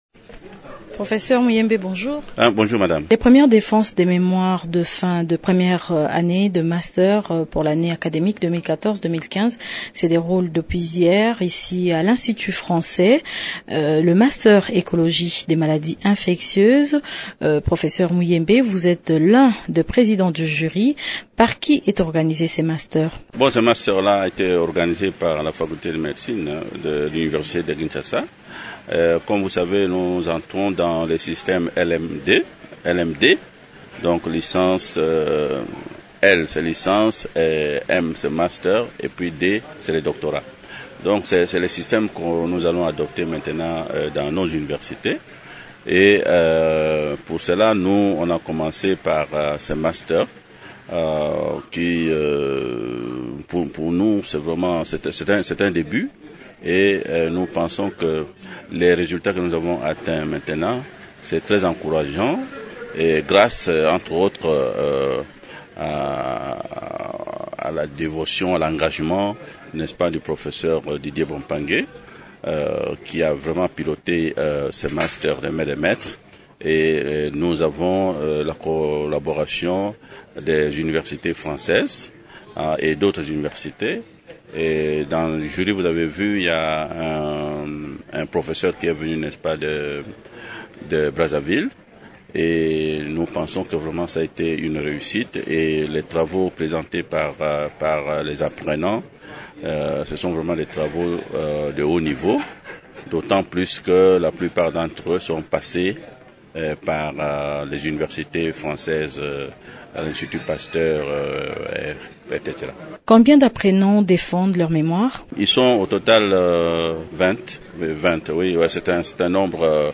Le Professeur Muyembe Tamfum, l’un des présidents de jury, est l’invité de Radio Okapi ce matin.